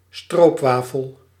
kiejtése), illetve tulajdonképpen siroopwafel, azaz szirupos ostya egy holland sütemény, gofriszerű kerek ostya karamellás töltelékkel, melyhez néha mogyorót vagy más ízesítőt kevernek.
Nl-stroopwafel.ogg